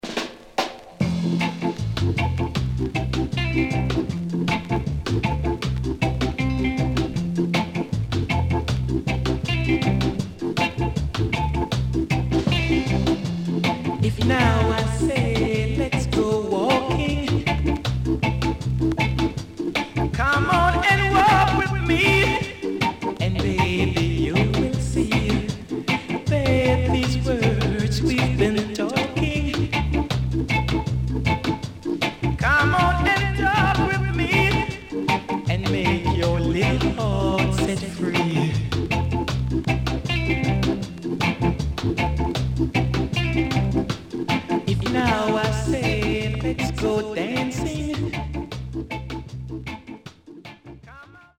EARLY REGGAE
SIDE A:所々チリノイズがあり、少しプチノイズ入ります。